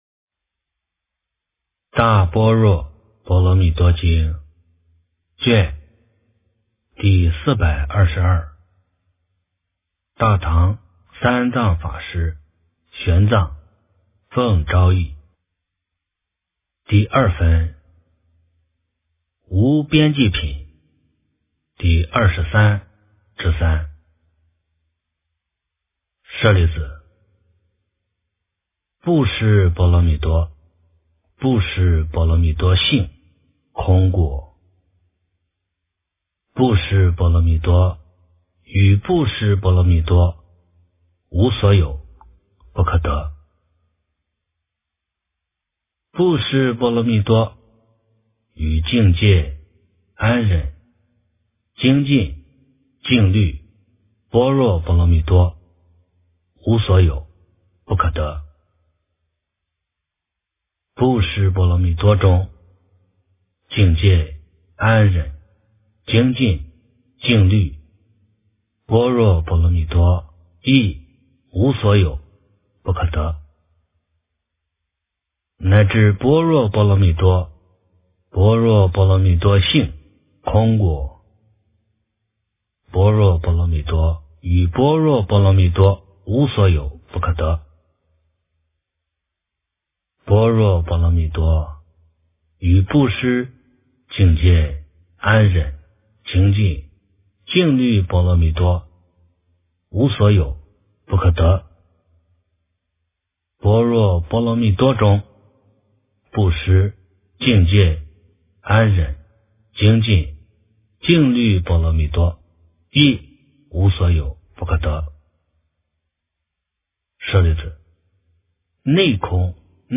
大般若波罗蜜多经第422卷 - 诵经 - 云佛论坛